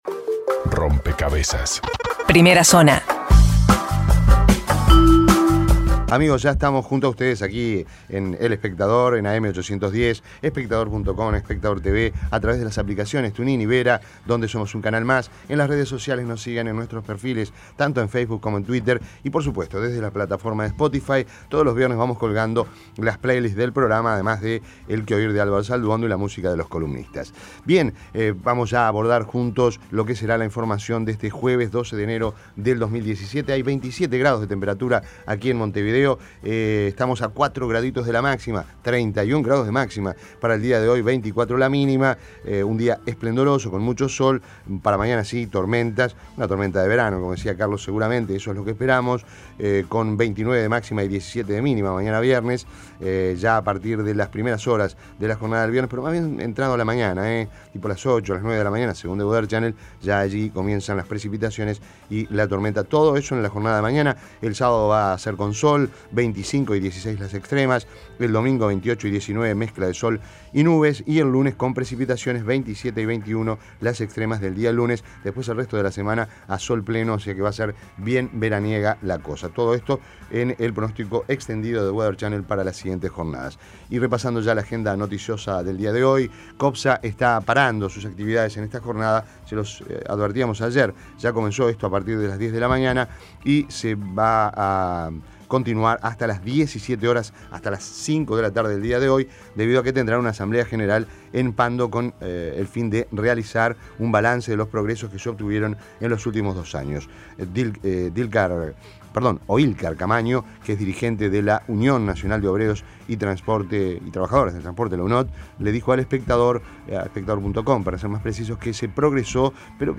Resumen de noticias Primera Zona Imprimir A- A A+ Las principales noticias del día, resumidas en la Primera Zona de Rompkbzas.